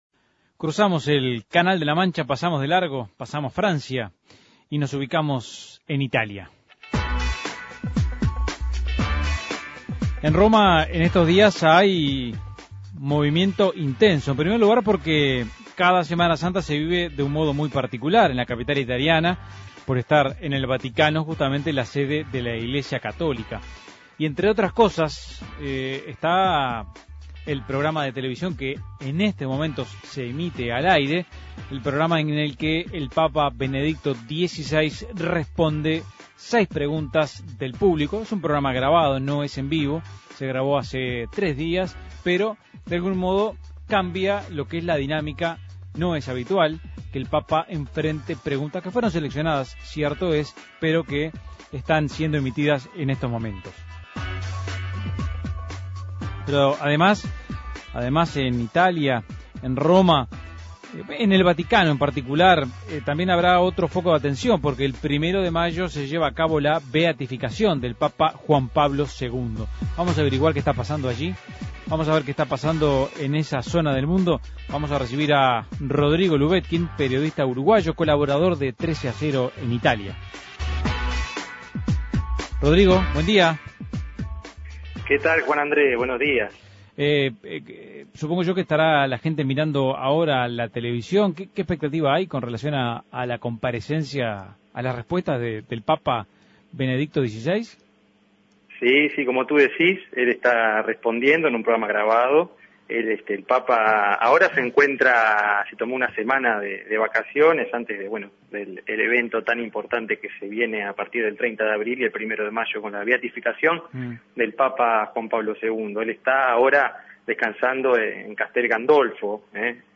periodista uruguayo radicado en Roma.